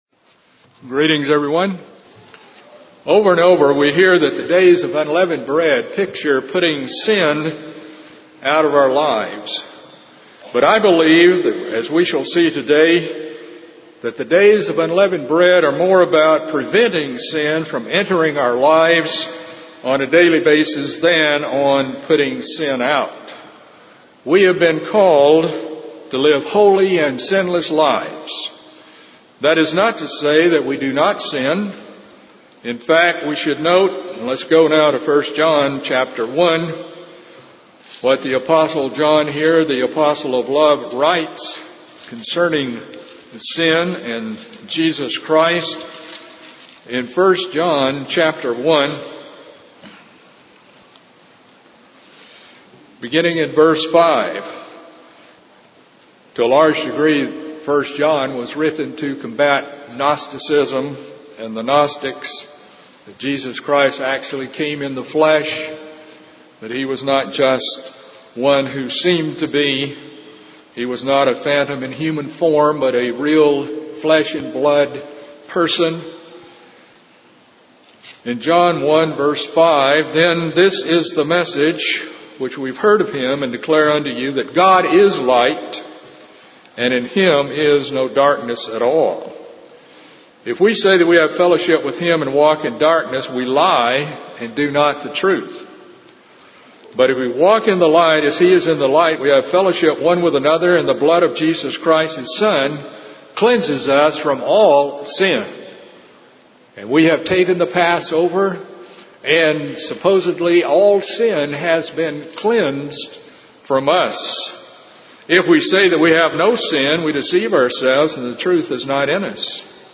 First Day of Unleavened Bread sermon. The Days of Unleavened Bread are about preventing sin from entering our lives on a daily basis.